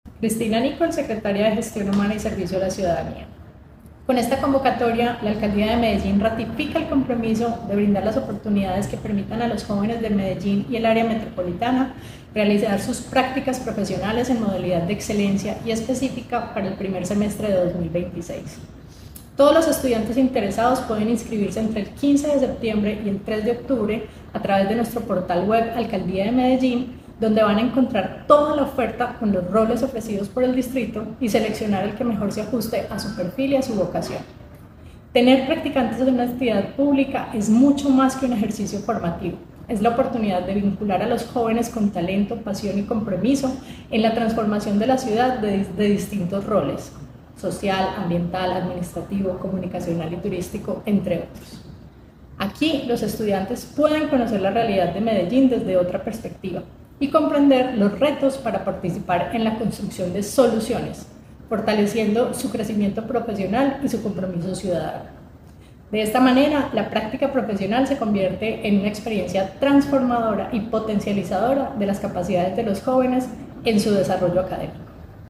Declaraciones de la secretaria de Gestión Humana y Servicio a la Ciudadanía, Cristina Nicholls Villa
Declaraciones-de-la-secretaria-de-Gestion-Humana-y-Servicio-a-la-Ciudadania-Cristina-Nicholls-Villa.mp3